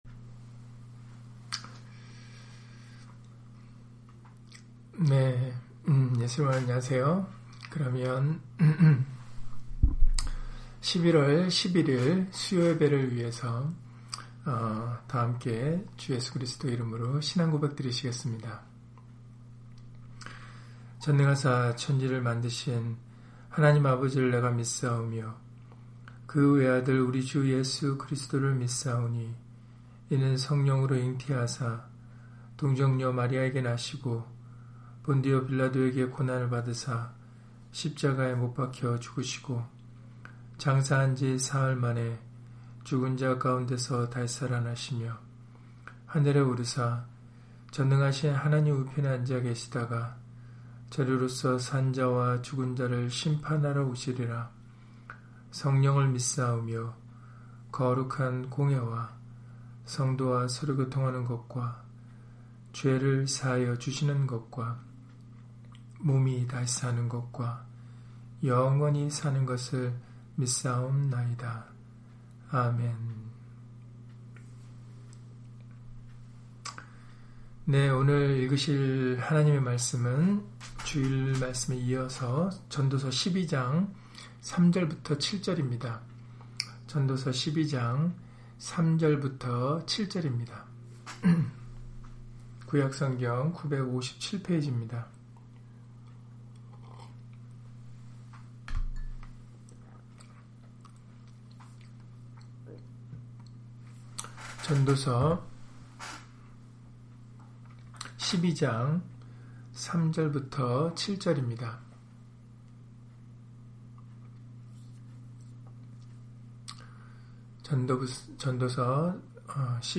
전도서 12장 3-8절 [노년의 때가 되기 전에 기억하라] - 주일/수요예배 설교 - 주 예수 그리스도 이름 예배당